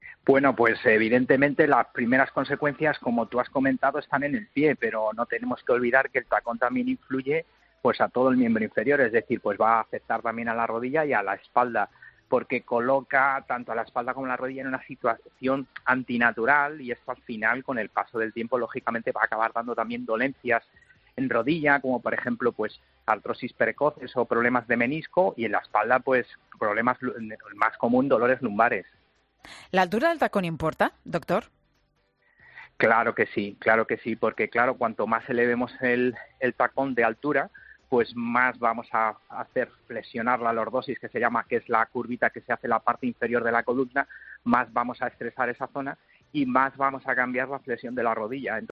Un doctor advierte en Mediodía COPE sobre las consecuencias del uso de tacones.